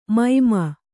♪ maima